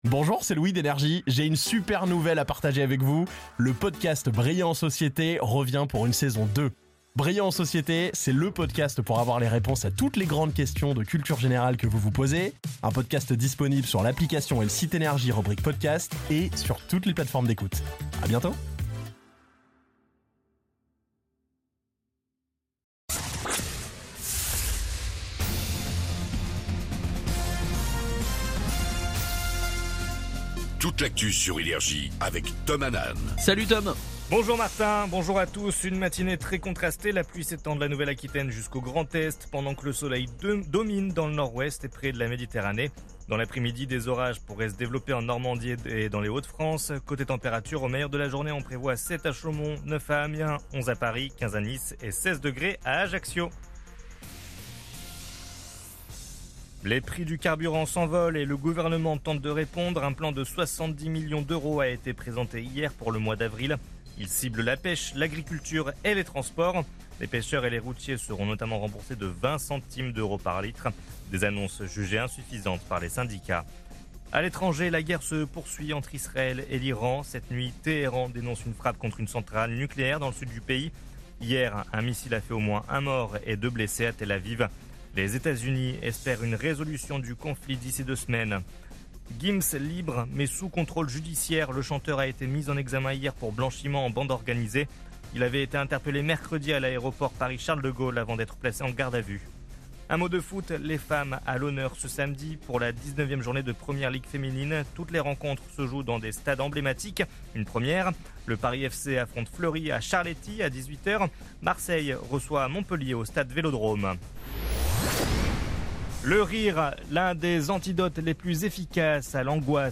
Réécoutez vos INFOS, METEO et TRAFIC de NRJ du samedi 28 mars 2026 à 07h30